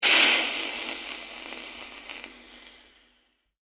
環境音 （38件）
手持ち花火を消火.mp3